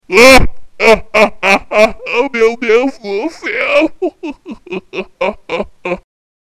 risadaloroza.mp3